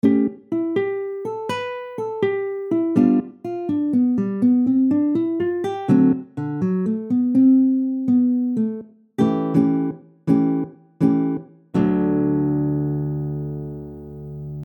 (Tabs are in Open D tuning.)
Let’s say we’re playing a blues song that has the chords D7-G7-A7. Over each chord we can play the corresponding Mixolydian mode.
mixolydian-lick-audio.mp3